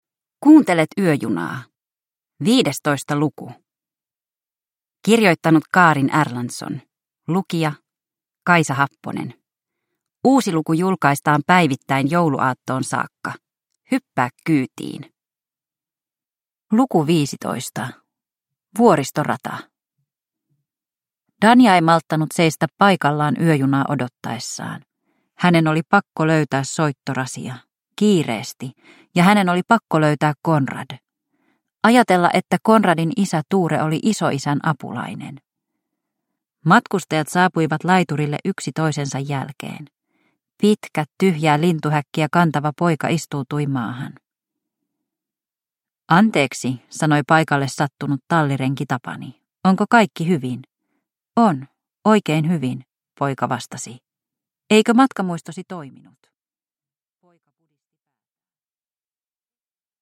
Yöjuna luku 15 – Ljudbok